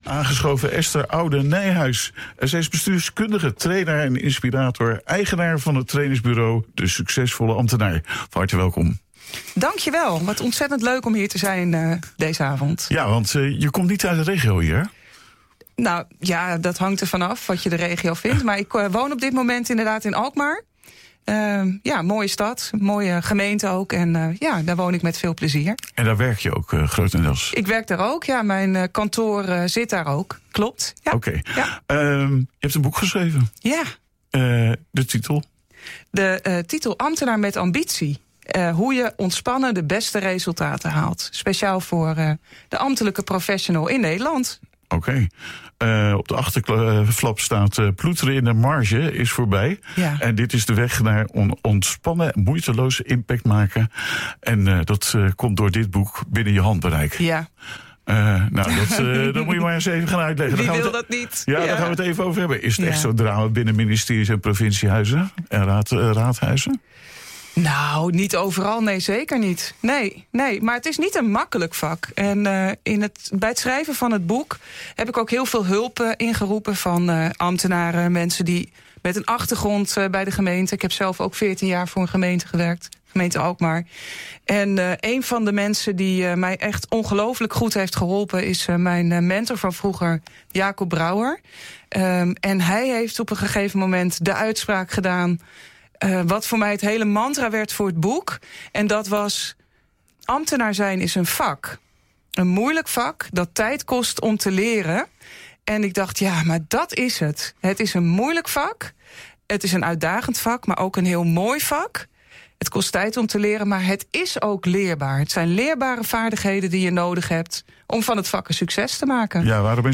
Twee maanden na het verschijnen vroegen we haar naar de studio te komen om haar werk en de inhoud van haar boek toe te lichten.